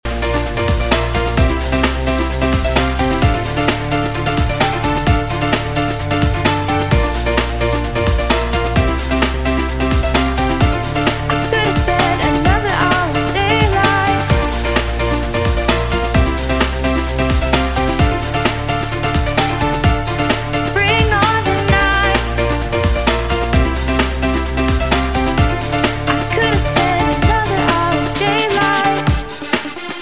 TOP >Vinyl >Grime/Dub-Step/HipHop/Juke